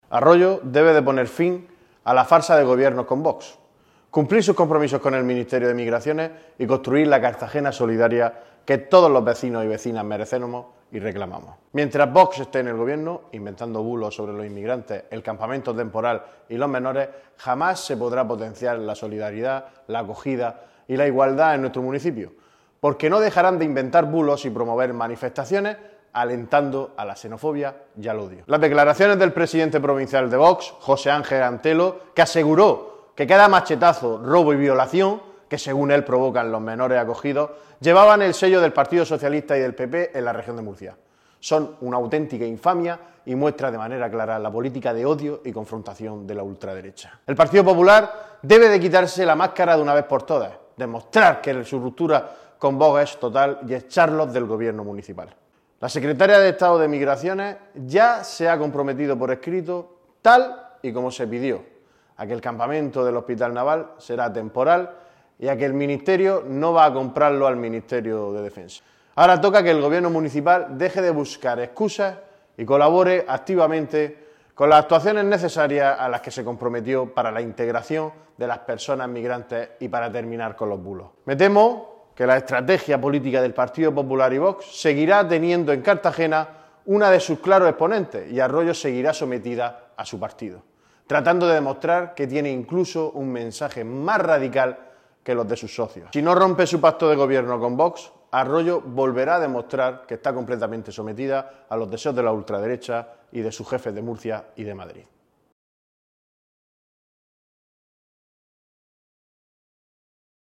El portavoz socialista señala que con la ultraderecha no se podrá construir la Cartagena solidaria que merecen los vecinos